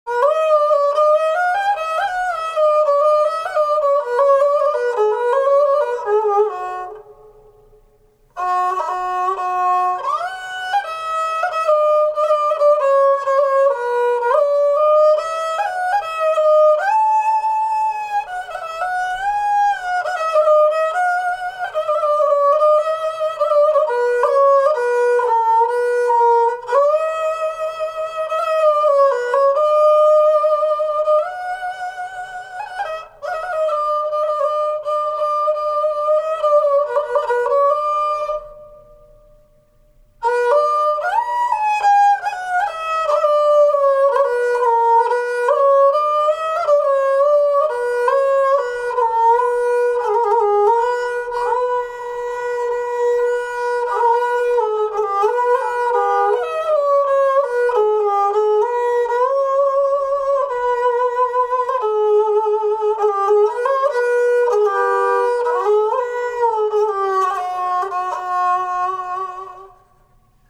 Genre: Turkish & Ottoman Classical.